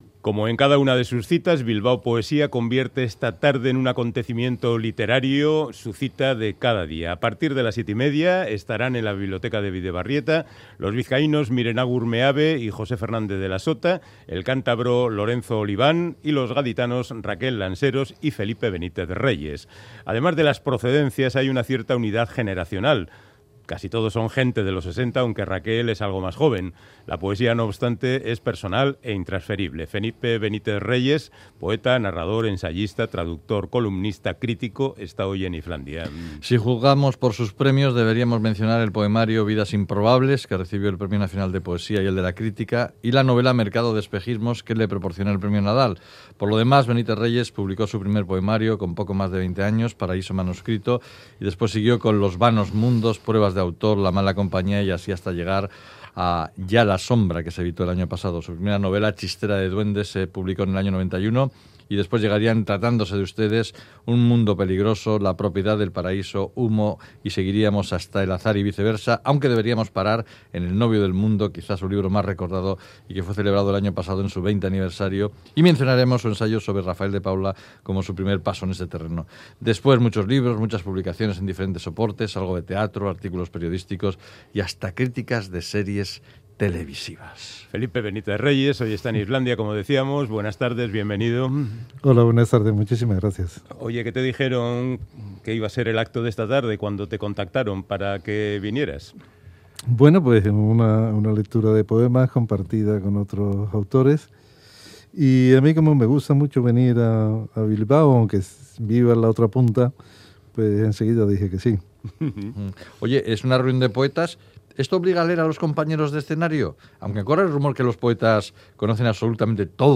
Audio: Charlamos con el narrador y poeta andaluz Felipe Benítez Reyes que participa en el festival Bilbao Poesía que organiza la Biblioteca de Bidebarrieta